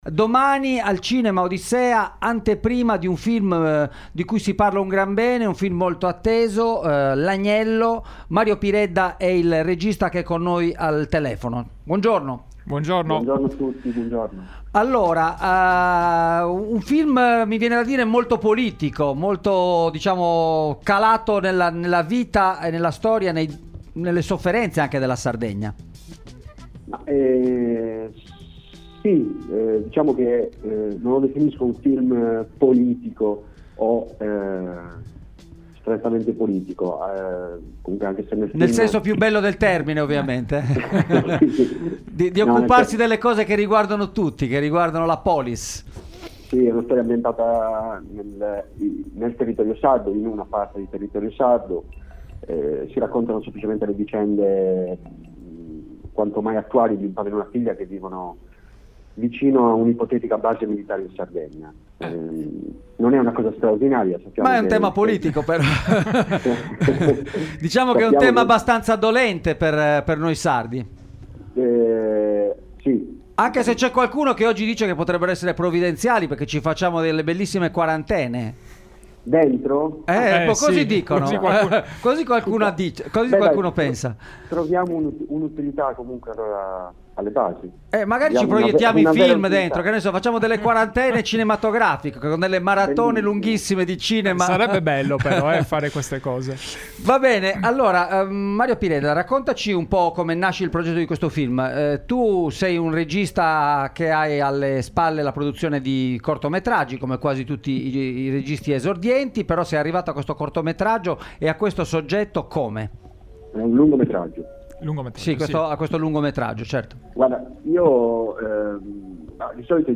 intervista
In studio